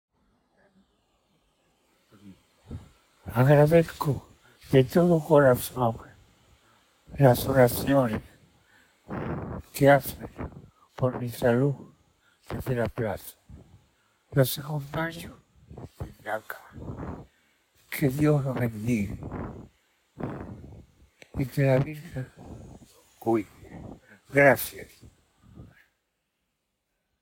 At last night’s Rosary for Pope Francis’ health, held in St. Peter’s Square, a surprise audio message from Pope Francis was played. In the 27-second message, Francis’ breathing is labored; a slight hiss, presumably from his oxygen machine, runs through the background. He struggles at one point to get a word out. But his voice is unmistakable and strong enough that, at the end of the message, his final word echoed off the Vatican’s stone façade: “Grazie.”
Parole di ringraziamento del Santo Padre Francesco.mp3